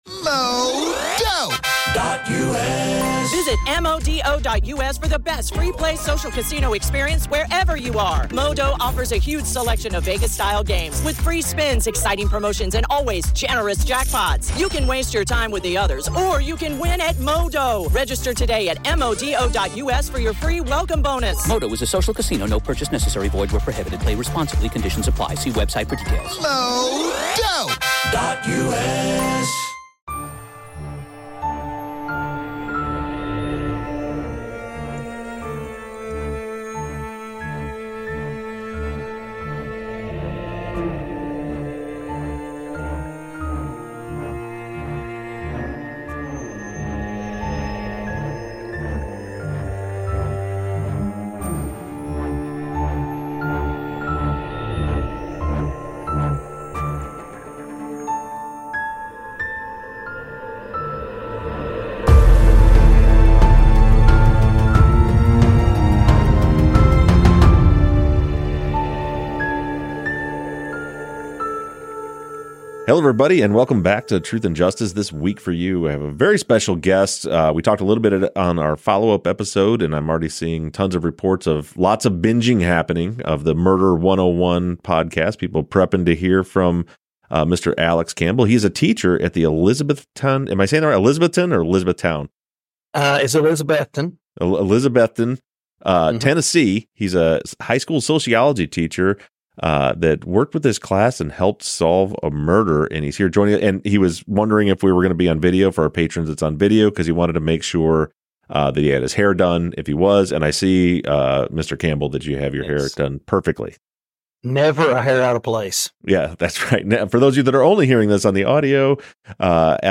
Interview w